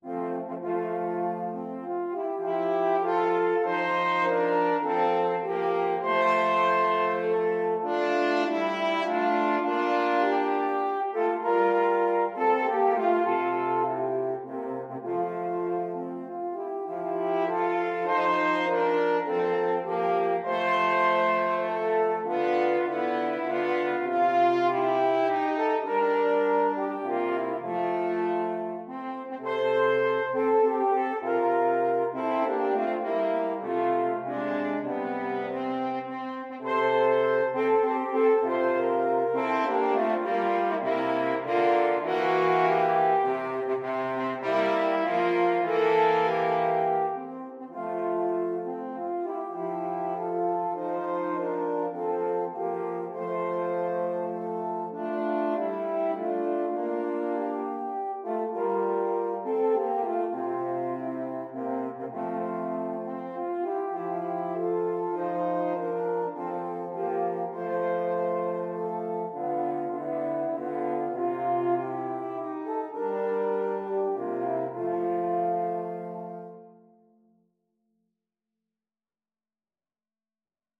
3/4 (View more 3/4 Music)
Classical (View more Classical French Horn Trio Music)